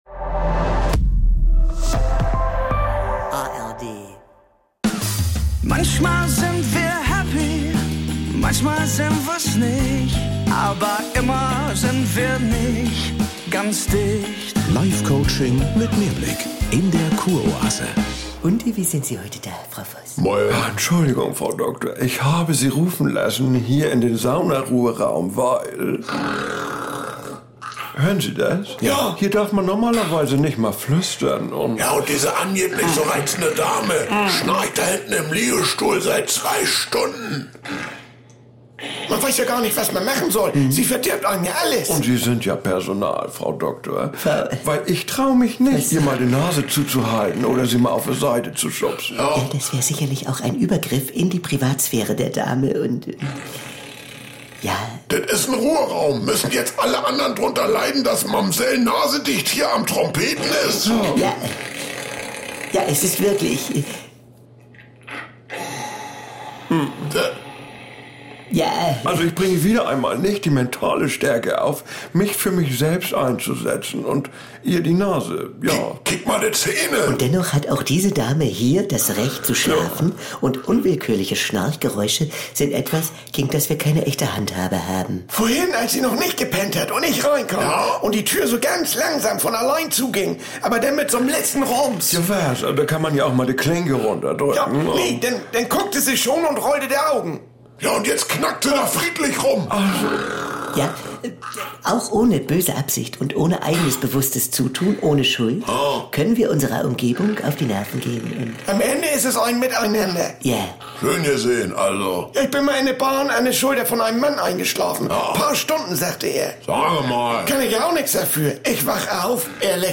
Ein bisschen Entspannung nach den zähen Wochen des Bundestagswahlkampfs haben wir uns alle verdient. Doch im Ruheraum des Saunabereichs der Kur-Oase ist eine Dame ungeniert am Schnarchen...